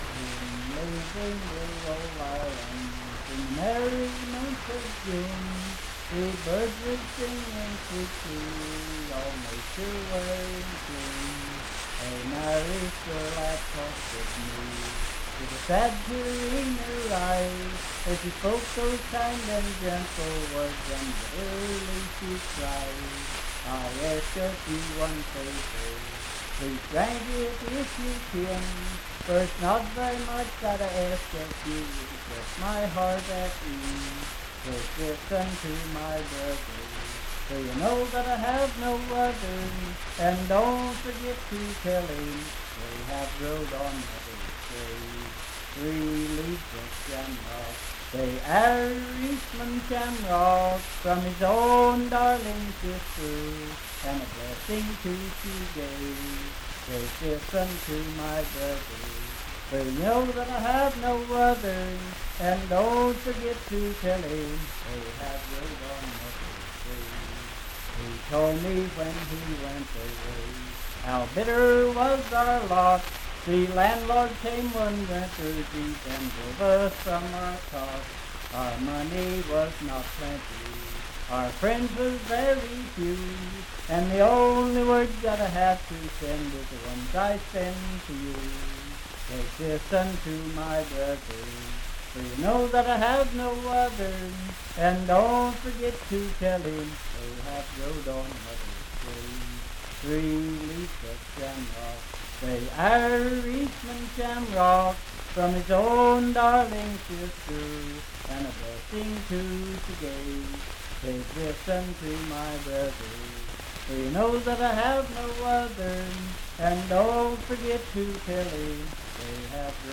Unaccompanied vocal music
in Riverton, Pendleton County, WV.
Verse-refrain 4d(4) & R(4).
Ethnic Songs
Voice (sung)